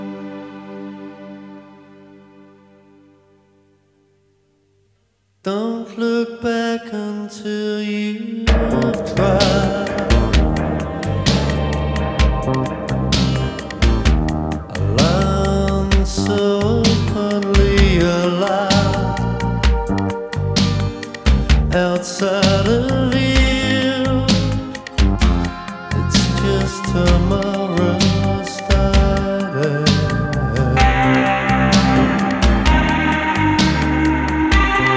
Pop Alternative Rock New Wave Prog-Rock Art Rock
Жанр: Поп музыка / Рок / Альтернатива